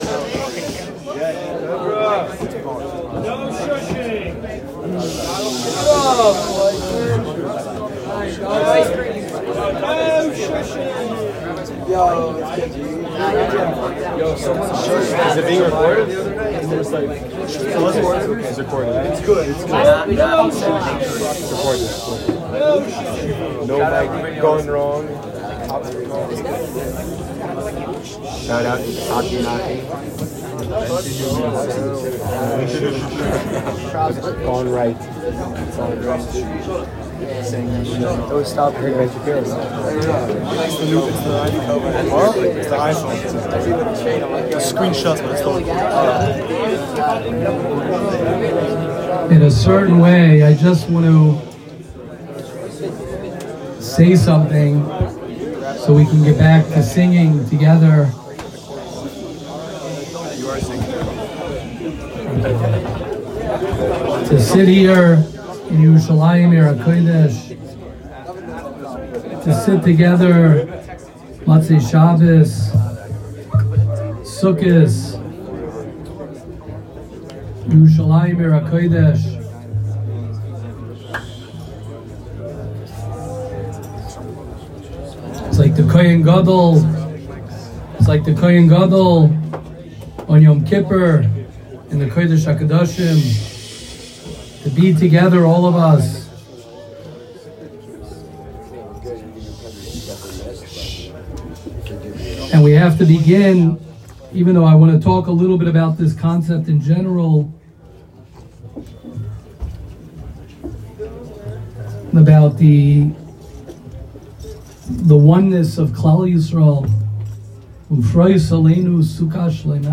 Shiur @ Simchas Beis Ha’shoeva in Givat Hamivtar